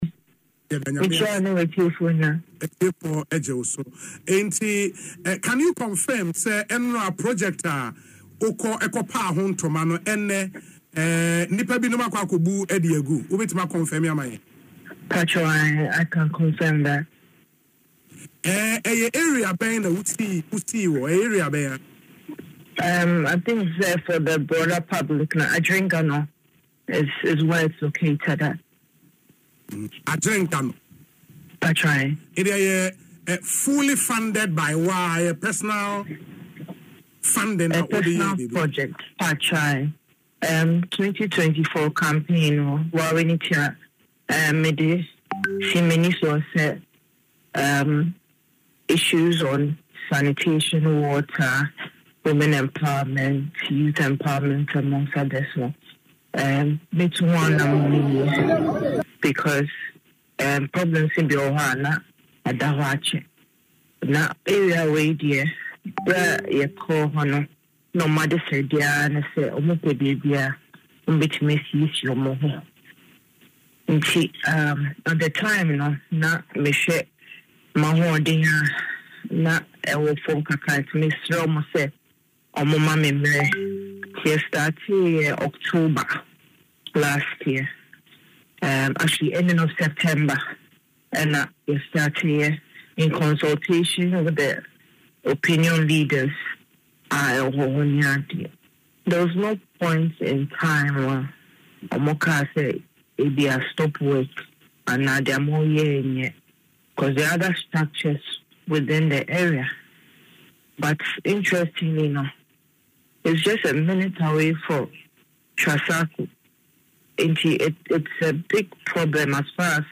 The project before the demolition In an interview on Asempa FM’s Ekosii Sen , the former Deputy Chief Executive Officer of the National Youth Authority (NYA) expressed disappointment over the destruction. According to her, the project began in October 2024 and cost about GH₵70,000. The project before the demolition She stated that the demolition was carried out by individuals claiming to be officials from the Ghana Grid Company (GRIDCo), who arrived in a branded pickup vehicle.